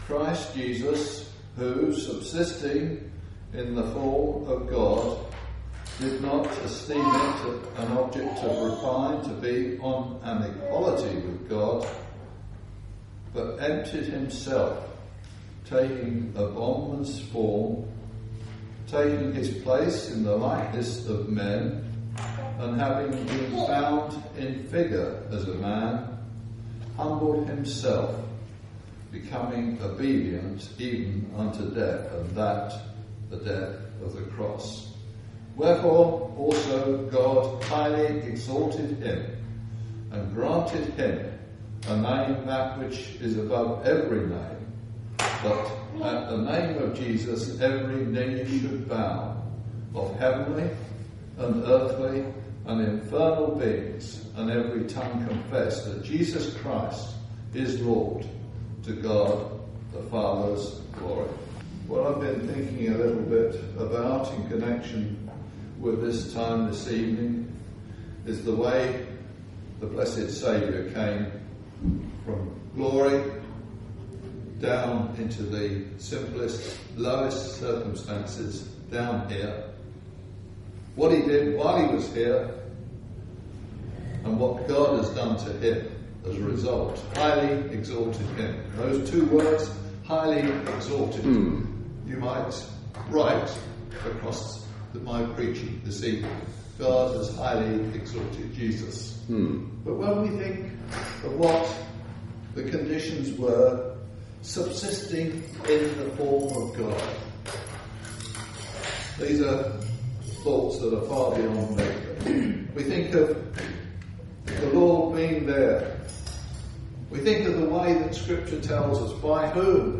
We are told from the Bible that Jesus came into this world to seek and to save that which was lost. Listen to this Gospel preaching, and find out how you can know peace with God because of the wondrous work of his beloved son who he has highly exalted.